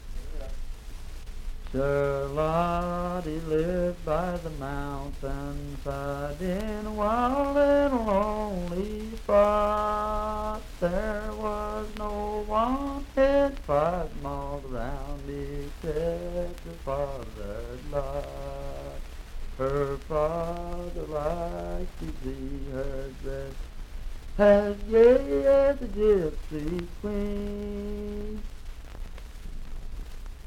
Unaccompanied vocal music
Verse-refrain 2(4).
Voice (sung)
Franklin (Pendleton County, W. Va.), Pendleton County (W. Va.)